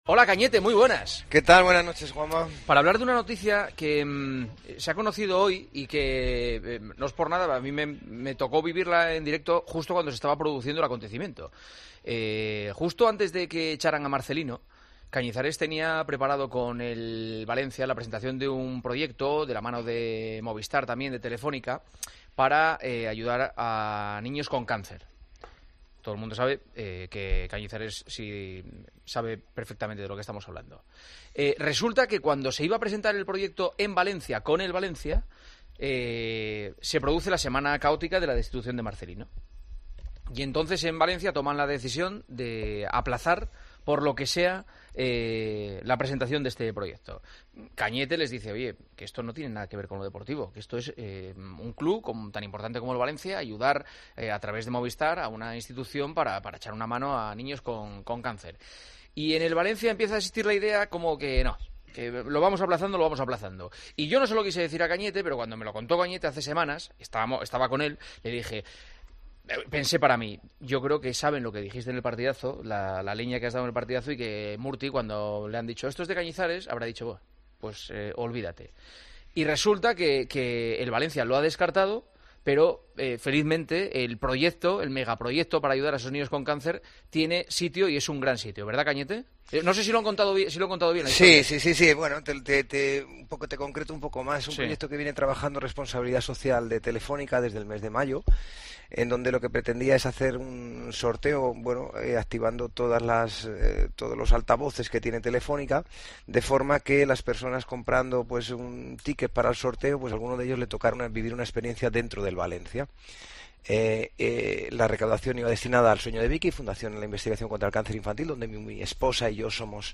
Cañizares dio todas las explicaciones en El Partidazo de COPE